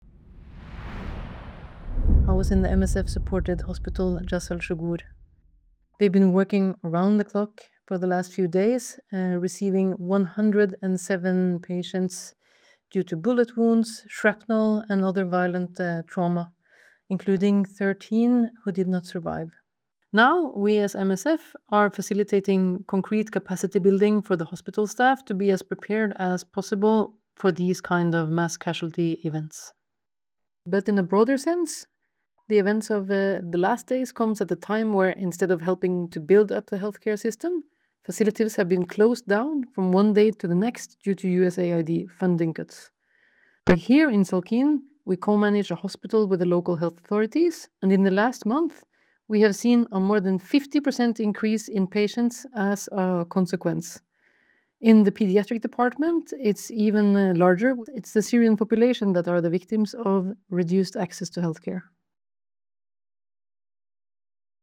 MSF voicenote update on northwest Syria